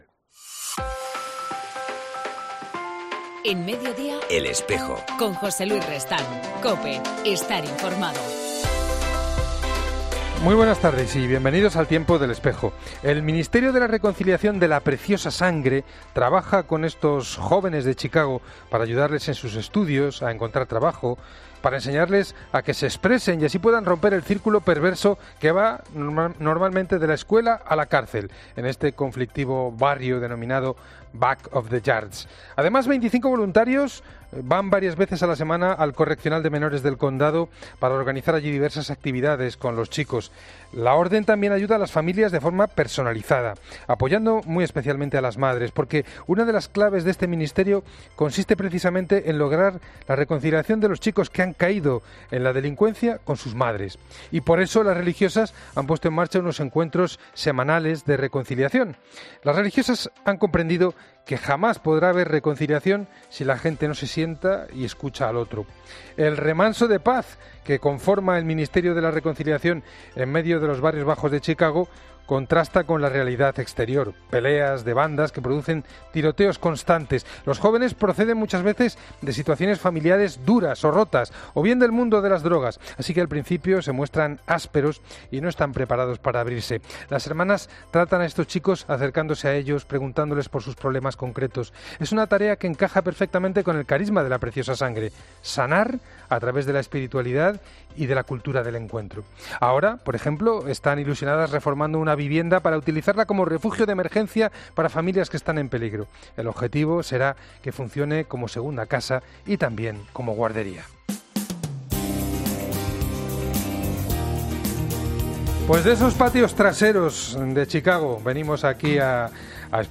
En El Espejo el 30 de enero entrevistamos a Ginés Gracía Beltrán, obispo electo de Getafe